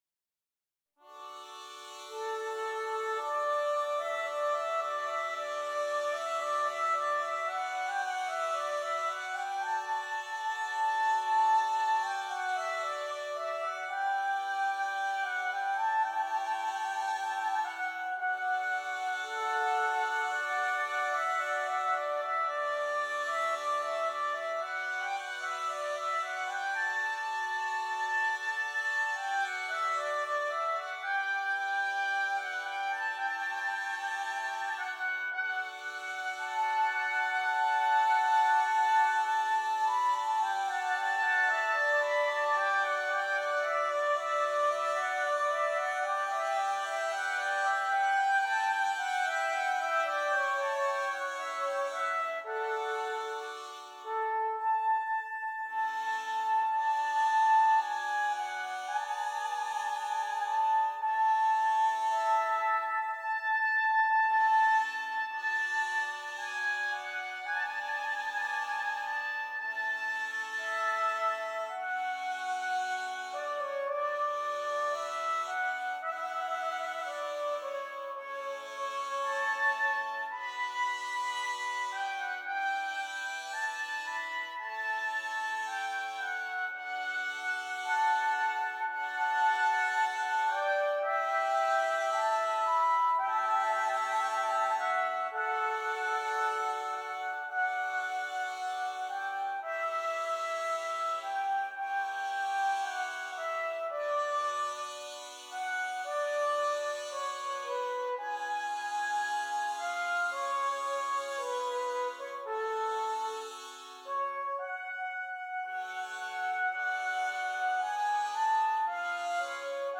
5 Trumpets and Soprano